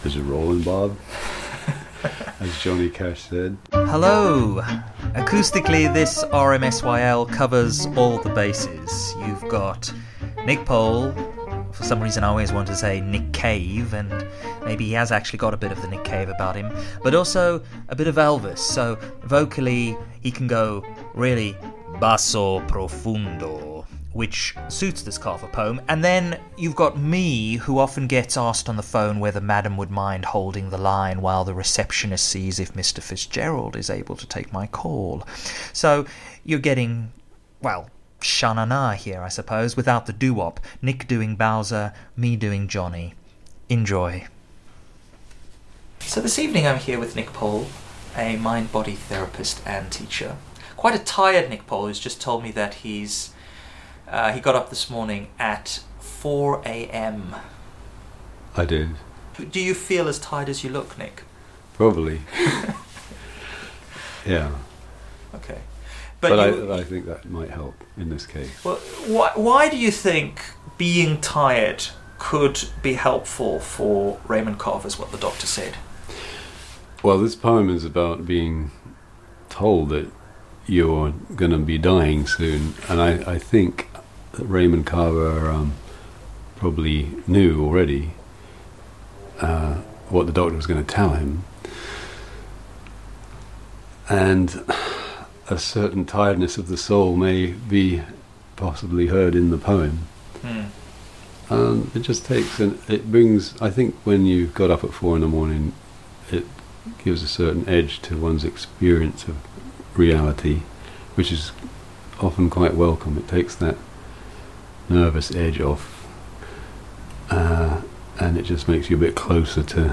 those BASSO PROFUNDO shoes